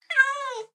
sounds / mob / cat / meow1.ogg
meow1.ogg